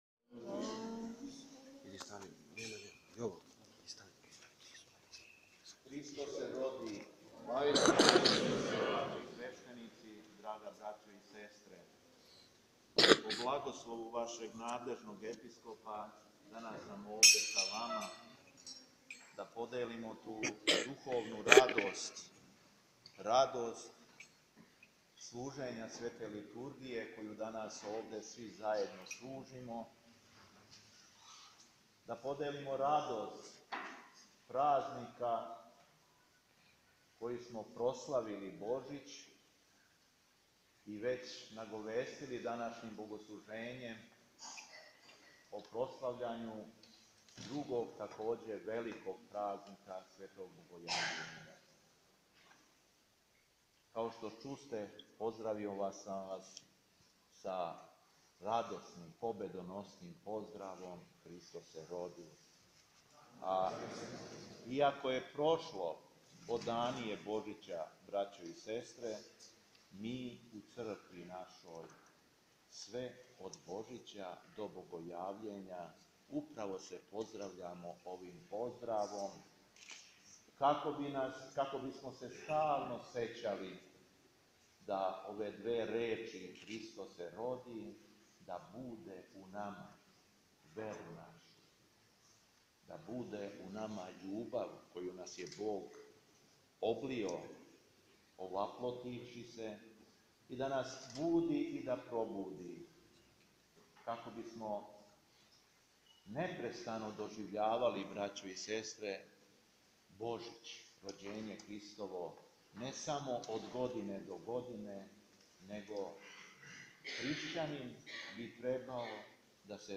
Његово Преосвештенство Епископ шумадијски Г. Јован, администратор Епархије ваљевске у време боравка Епископа Милутина у Митрополији аустралијско – новозеландској, 15. јануара 2017. године служио је Свету Архијерејску Литургију у храму Силаска Светог Духа на апостоле у Обреновцу, уз саслуживање епарх...
Беседа Епископа шумадијског Г. Јована
По прочитаном Јеванђељу, Епископ Јован се обратио сабраном народу беседом.